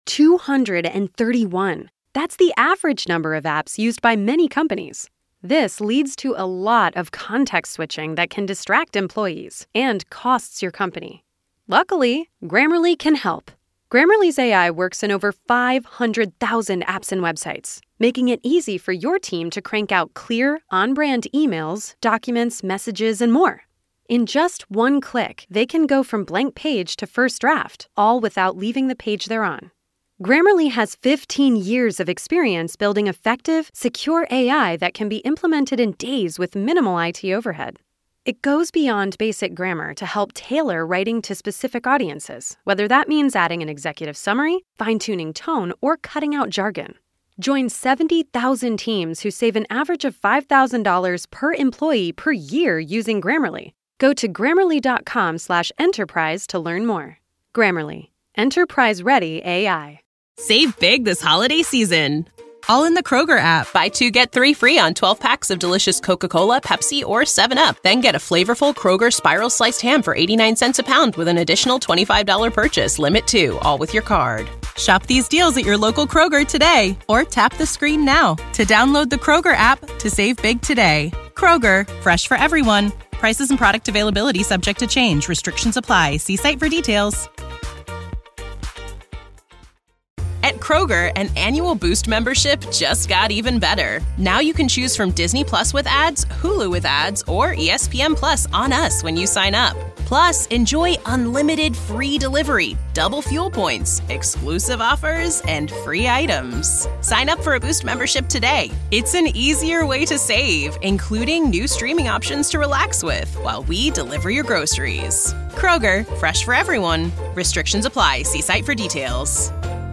Interview
Shoot Interviews Dec 21